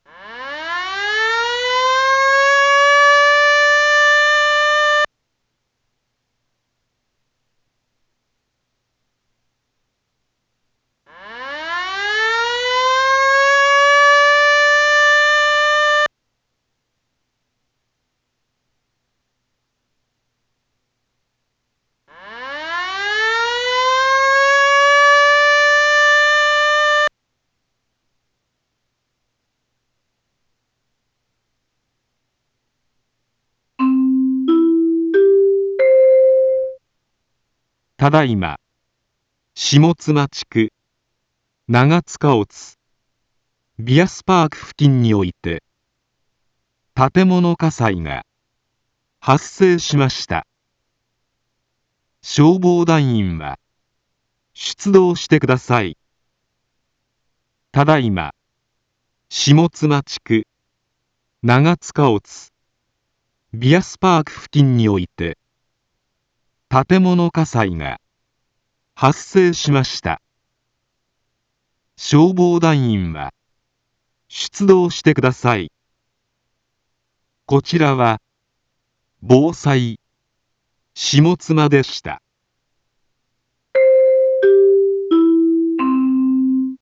一般放送情報
Back Home 一般放送情報 音声放送 再生 一般放送情報 登録日時：2023-10-13 00:45:30 タイトル：火災報 インフォメーション：ただいま、下妻地区、長塚乙、 ビアスパーク 付近において、 建物火災が、発生しました。